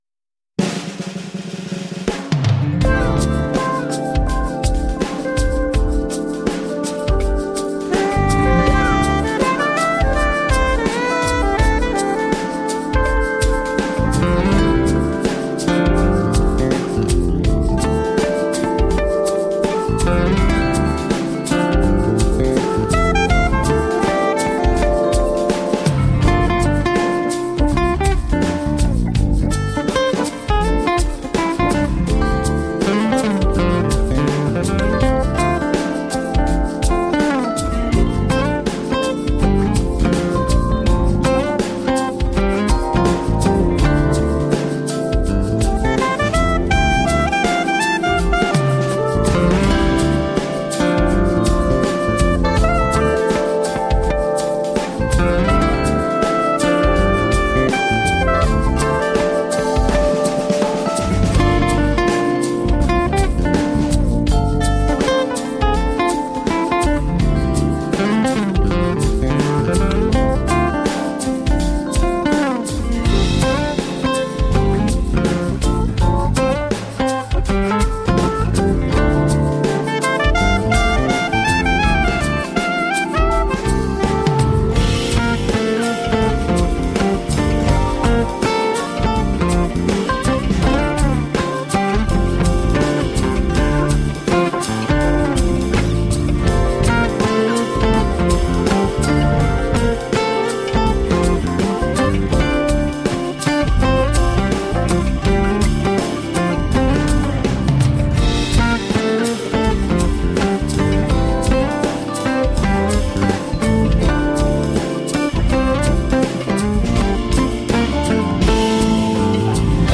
Groovy smooth jazz to brighten your day.